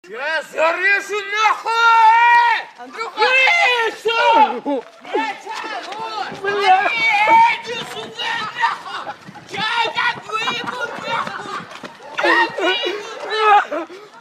угарные
нецензурная лексика
голосовые
Сигнал на смс.